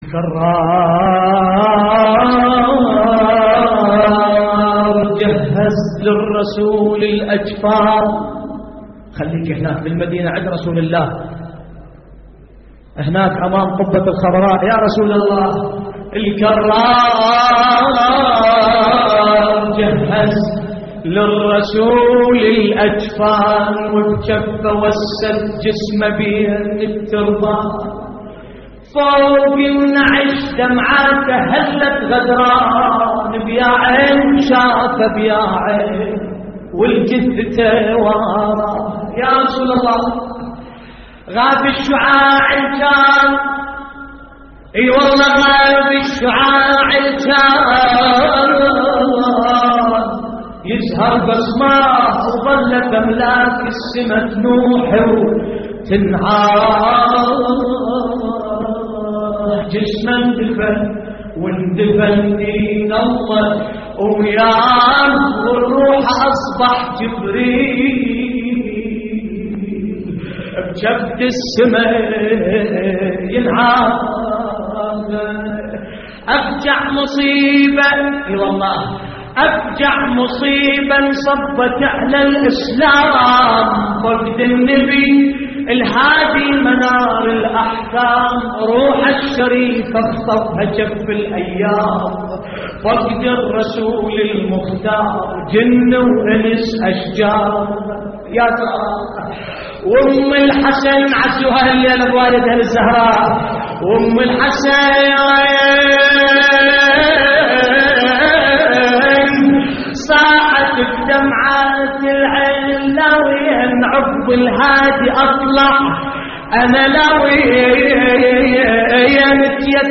نعي